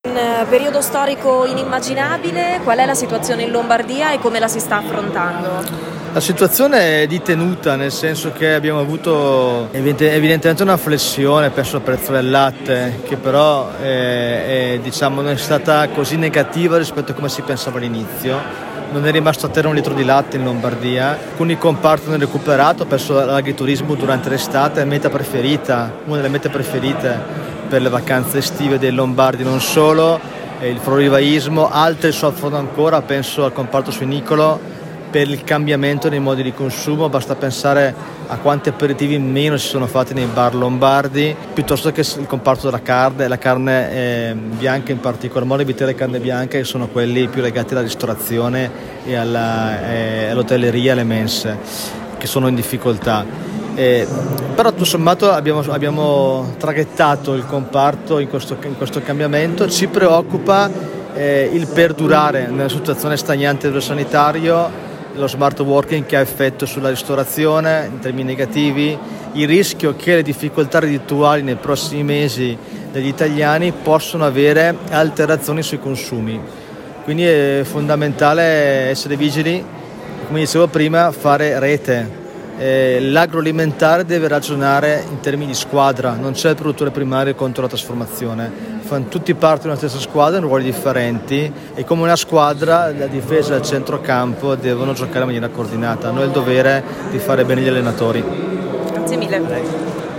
E’ stata ufficialmente inaugurata, nella serata di venerdì 4 settembre, la nuova edizione della Fiera Millenaria di Gonzaga, evento fieristico di punta della provincia di Mantova e non solo.
l’Assessore Regionale all’Agricolutra, Fabio Rolfi